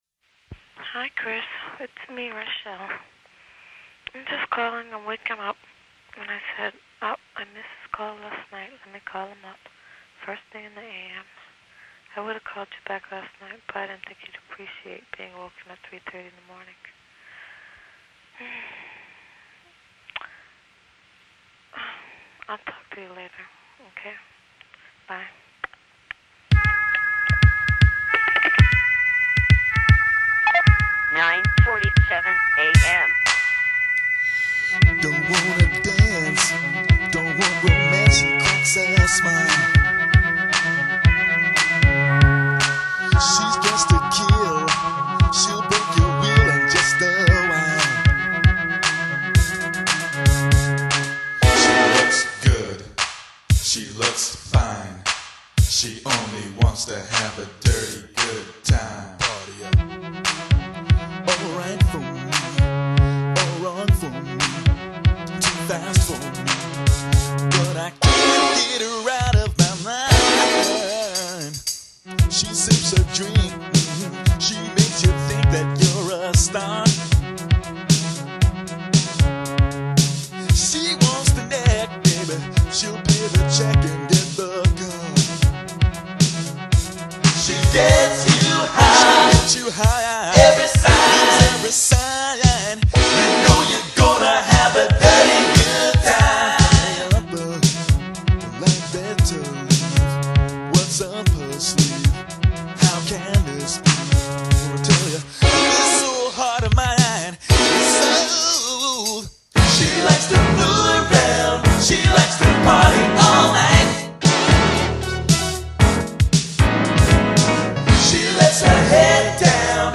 Here I perfected The Prince Scream, and there’s some pretty good playing here if you consider the fact I had no sequencers or samplers.